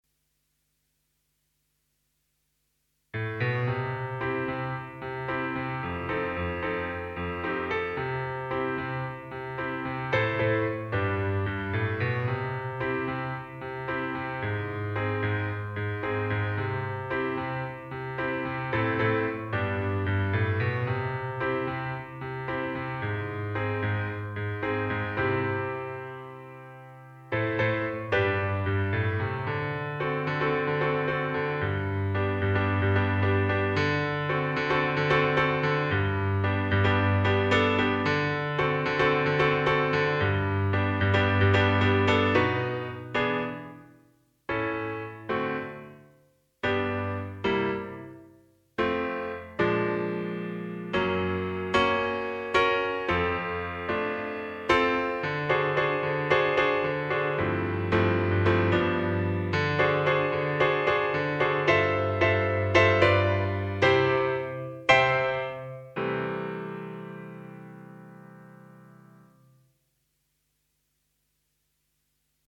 minus solo